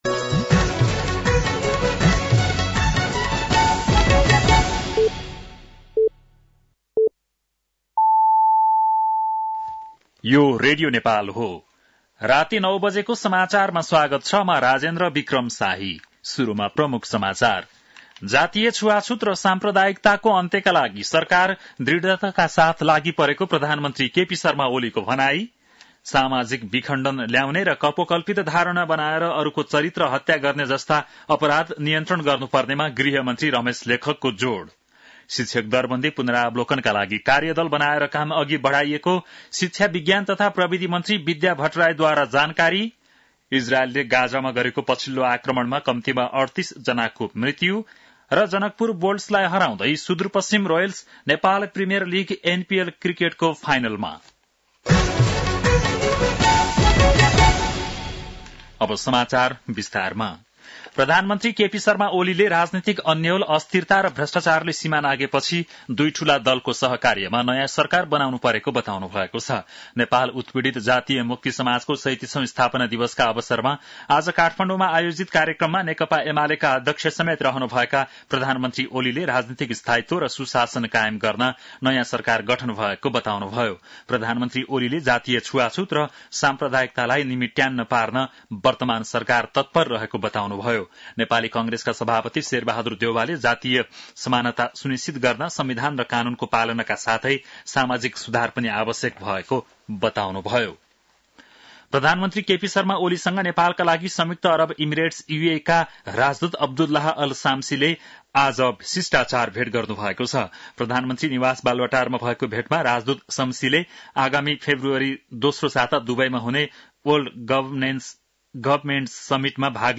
बेलुकी ९ बजेको नेपाली समाचार : ४ पुष , २०८१
9-PM-Nepali-NEWS-9-3.mp3